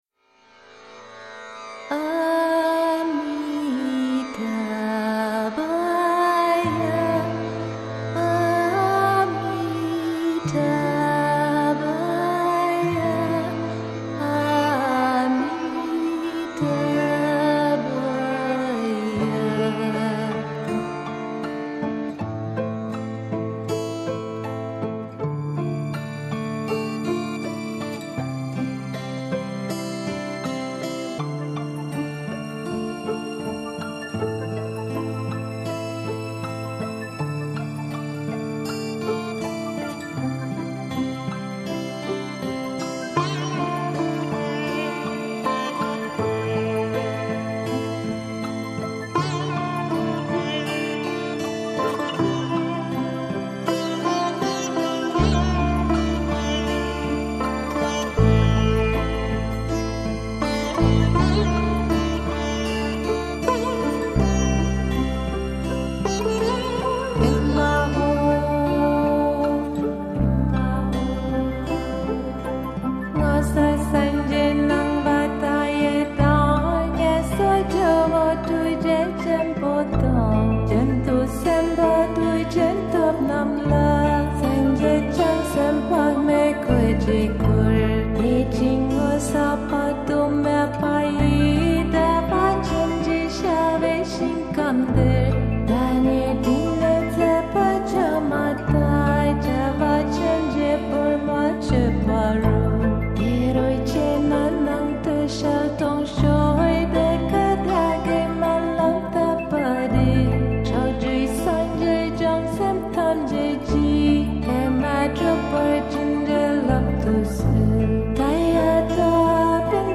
relax spiritual prayer